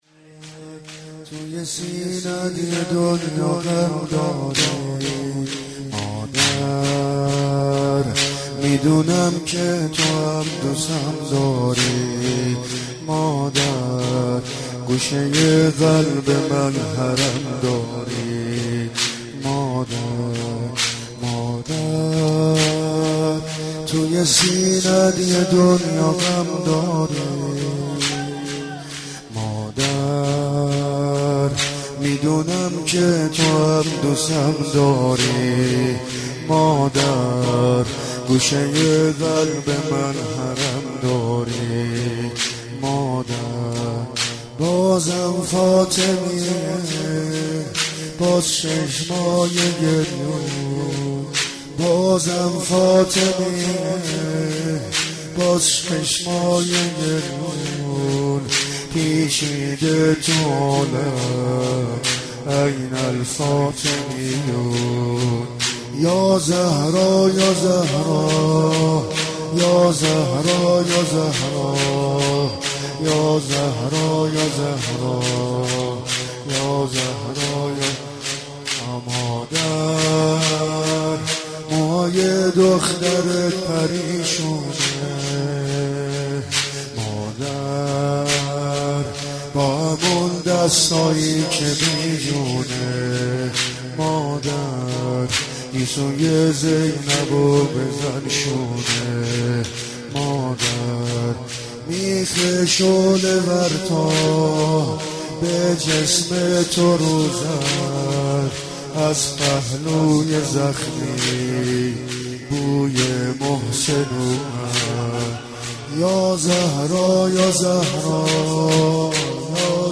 سینه زنی در شهادت «حضرت زهرا(س